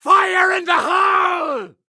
geometry-dash-fire-in-the-hole-sound-effect-1.mp3